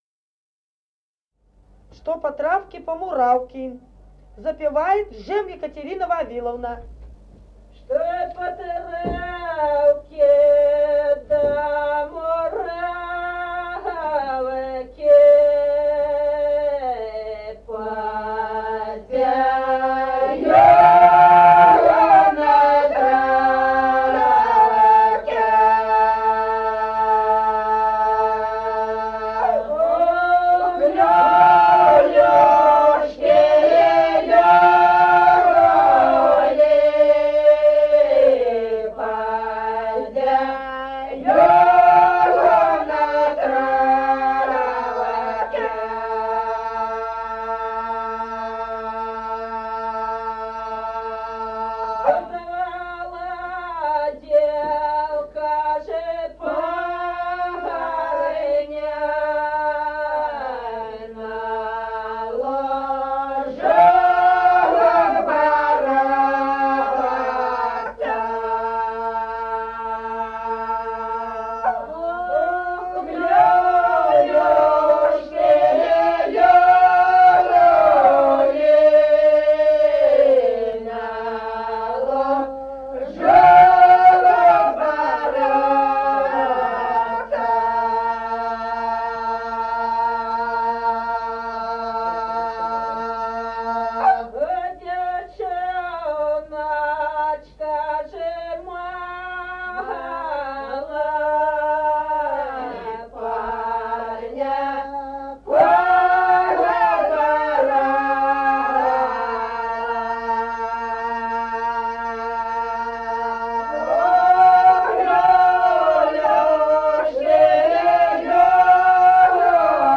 Народные песни Стародубского района «Что по травке да муравке», карагодная.